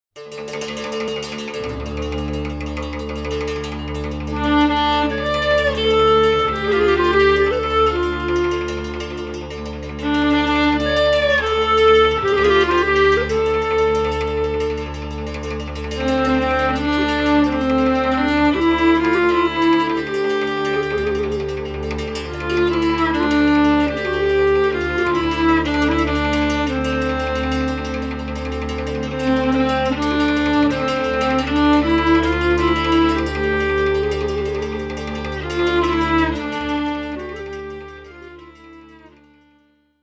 Violin
C Clarinet
Accordions, Tsimbl
Bass Cello
Genre: Klezmer.